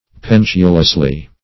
pendulously - definition of pendulously - synonyms, pronunciation, spelling from Free Dictionary Search Result for " pendulously" : The Collaborative International Dictionary of English v.0.48: Pendulously \Pen"du*lous*ly\, adv.